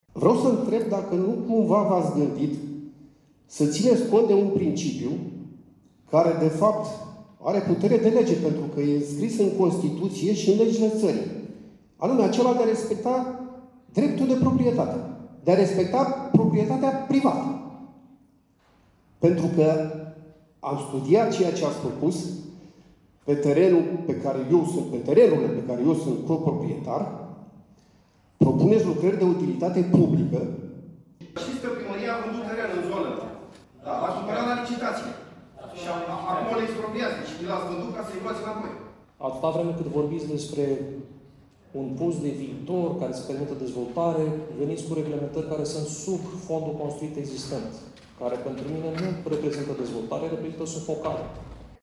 Sunt doar două din întrebările ridicate de participanții la dezbaterea publică a Planului Urbanistic Zonal al zonei centrale a municipiului Constanța.